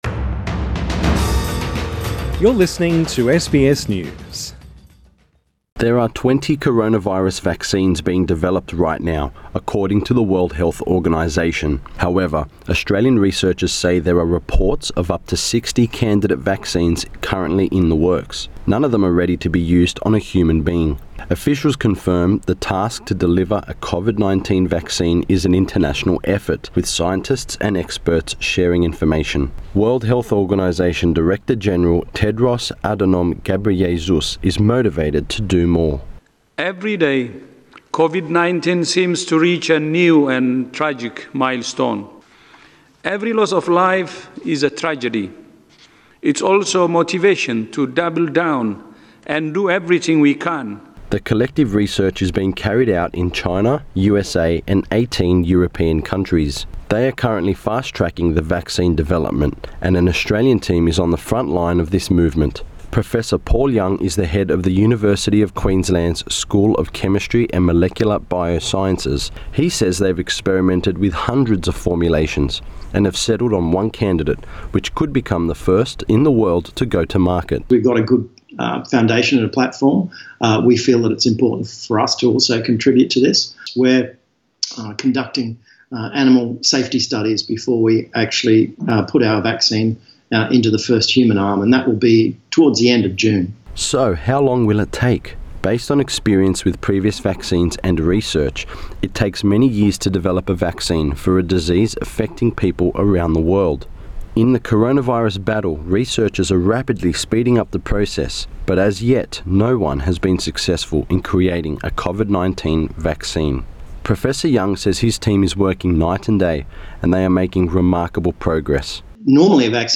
SBS News asks health experts, including those working on a COVID-19 vaccine, all the burning questions.